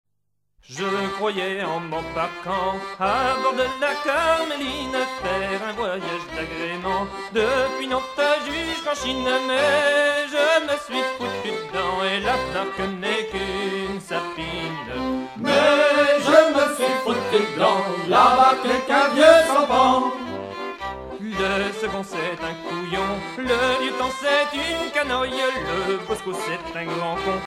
gestuel : à virer au cabestan
circonstance : maritimes
Genre strophique
Pièce musicale éditée